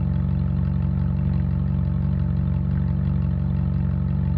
v8_07_idle.wav